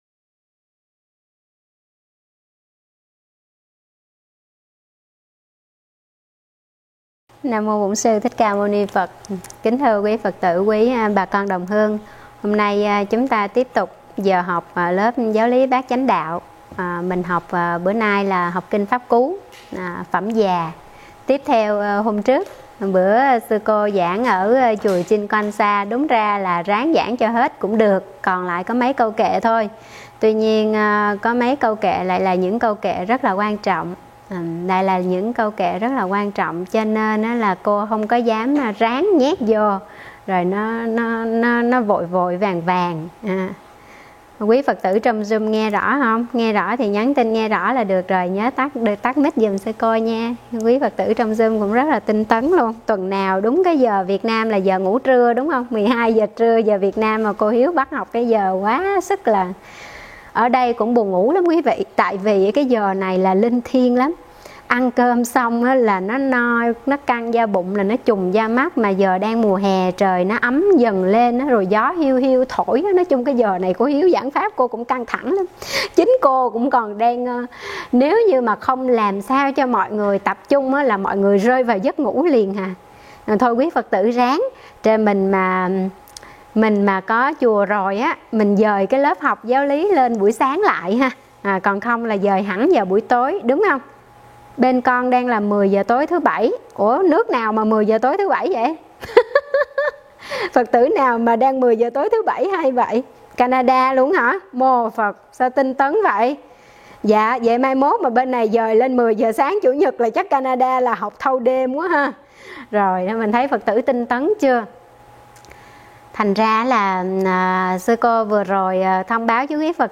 Mời quý phật tử nghe mp3 thuyết pháp Tuổi trẻ nên đầu tư vào gì?